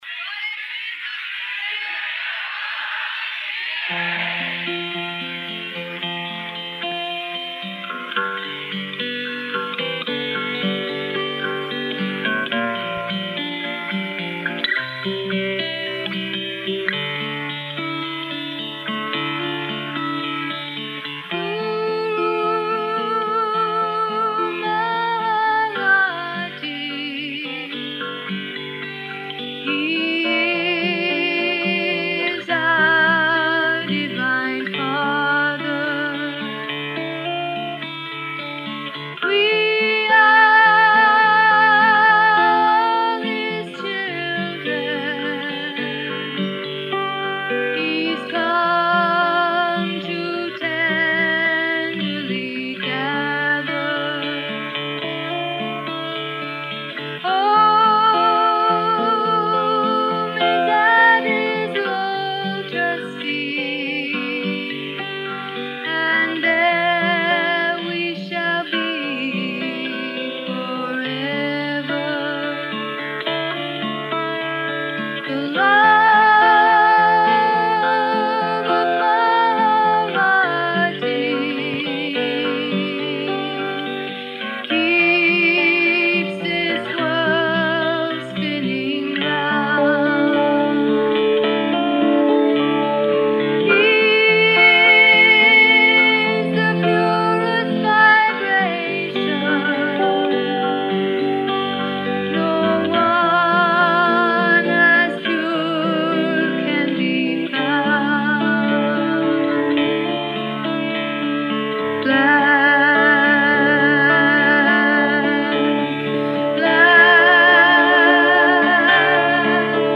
1980 BHAJANS RECORDED AT THE HOLI 1980 FESTIVAL